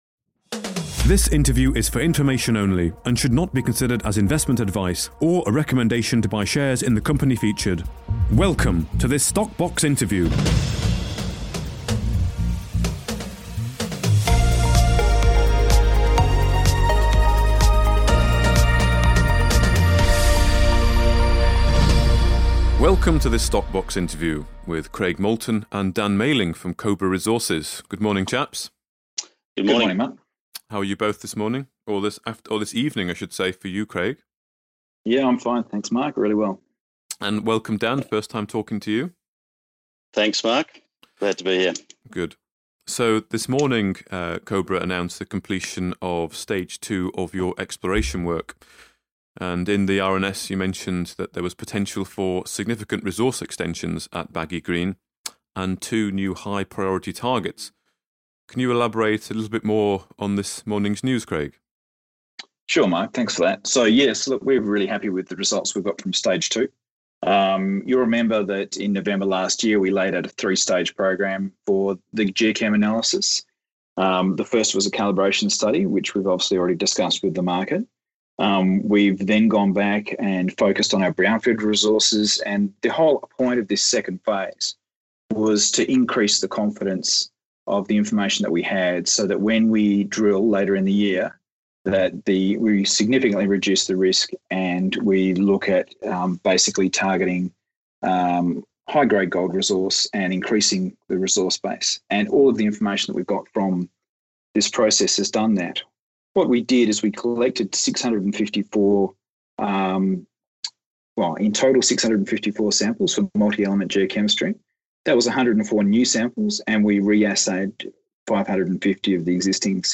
StockBox Podcasts / Interview